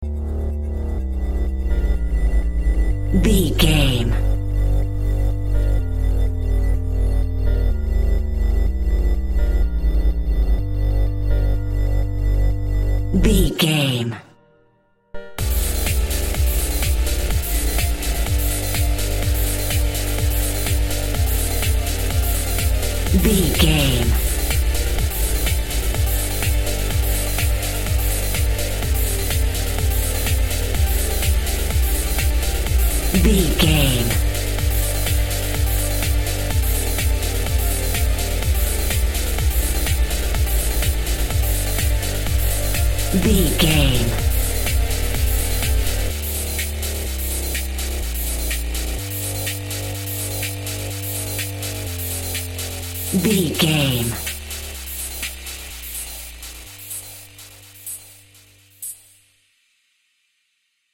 Epic / Action
Fast paced
Aeolian/Minor
Fast
dark
futuristic
groovy
aggressive
synthesiser
drum machine
house
techno
synth leads
synth bass
upbeat